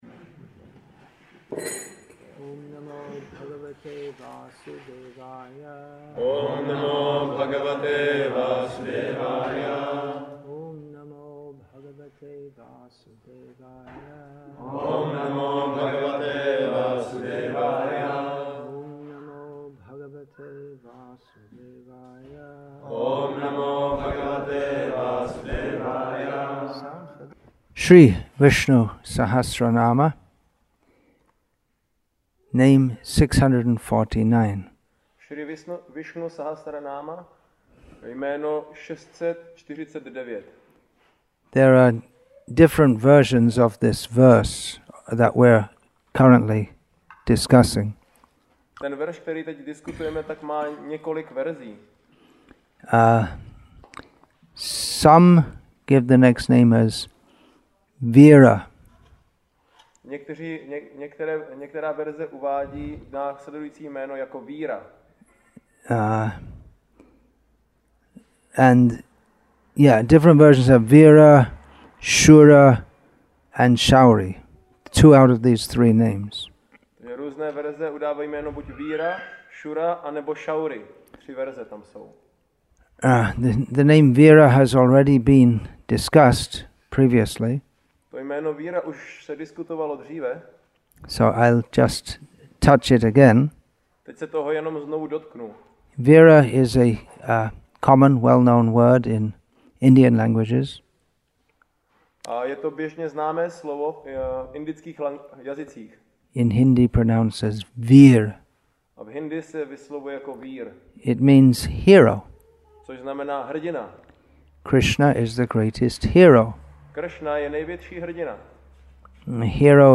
English with Český (Czech) Translation; Nava Gokula Farm, Czech Republic